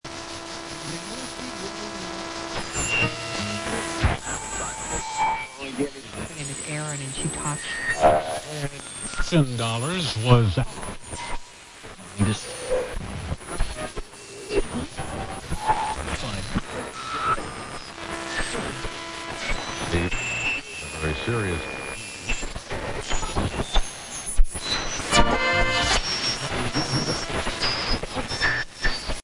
radio crackling
am-band-static-6036.mp3